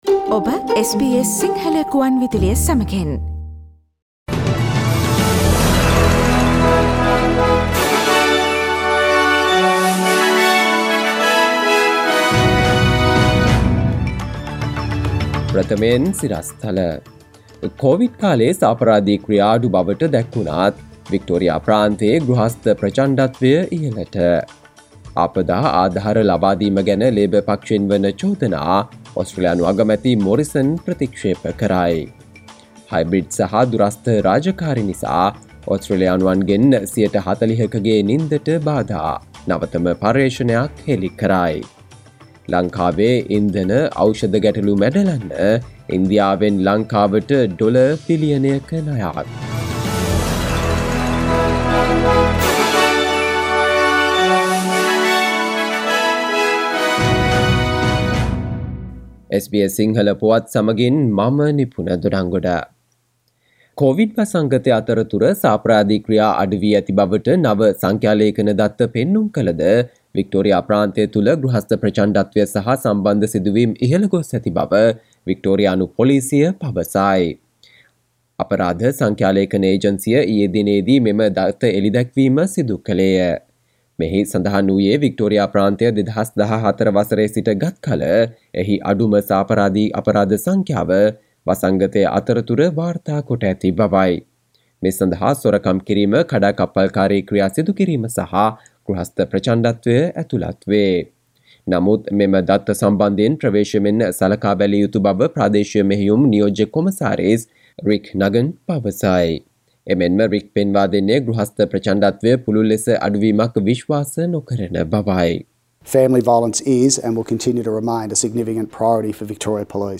සවන්දෙන්න 2022 මාර්තු 18 වන සිකුරාදා SBS සිංහල ගුවන්විදුලියේ ප්‍රවෘත්ති ප්‍රකාශයට...